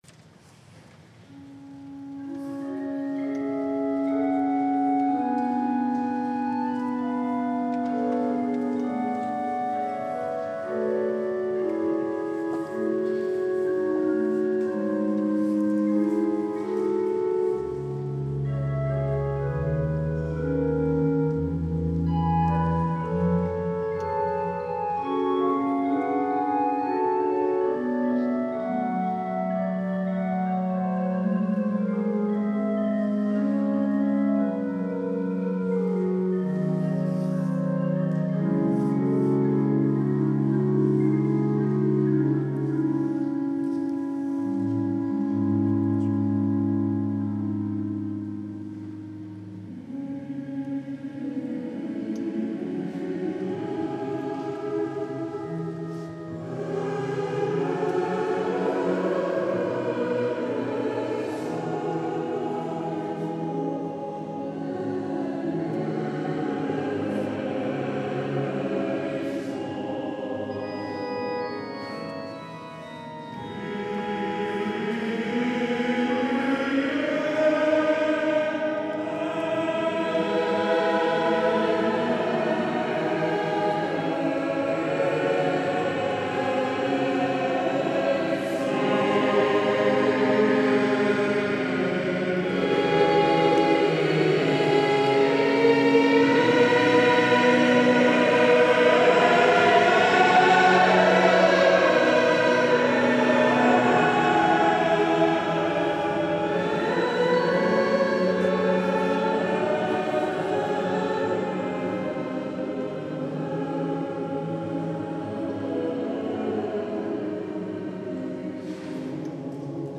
S. Gaudenzio church choir Gambolo' (PV) Italy
Dal concerto di Natale del 19 dicembre 2011 - " OMAGGIO A  PEROSI "